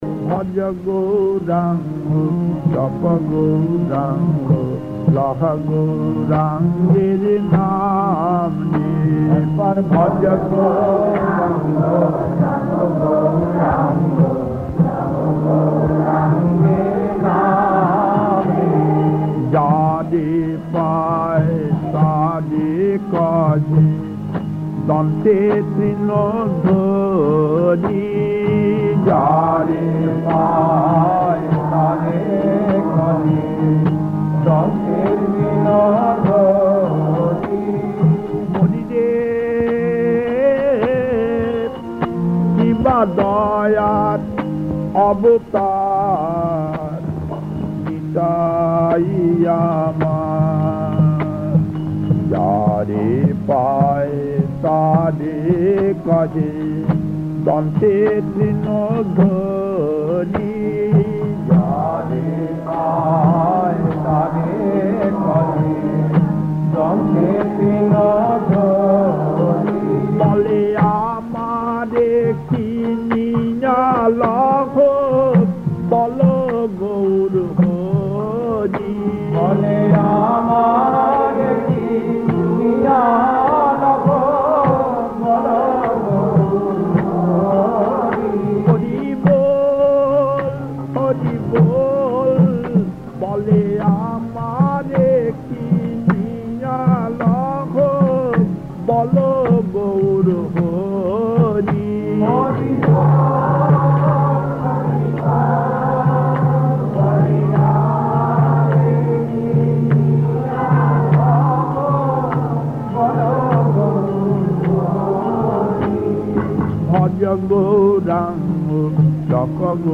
Kirtan Kirtan H5-2(N) 1: Bhojo Gourango 2: Mago Amar Ei 3: Ebar Ami Bujhbo 4: Jagorone Jay Bhibabori 5: Eshechhe Parer Tori 6: Bimol Probhate Shobey 7: Konthe Amar Nei 8: Bhobosagor Taron / Namo Narayan